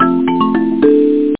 home *** CD-ROM | disk | FTP | other *** search / PSION CD 2 / PsionCDVol2.iso / Wavs / XylophoneSound ( .mp3 ) < prev next > Psion Voice | 1998-08-27 | 11KB | 1 channel | 8,000 sample rate | 1 second
XylophoneSound.mp3